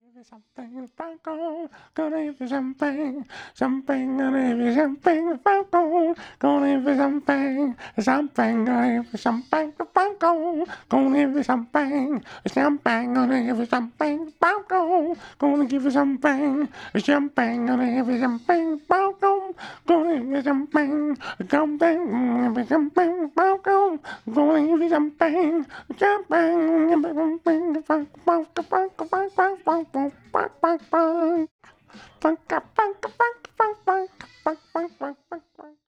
DF_107_E_FUNK_VOX_01 .wav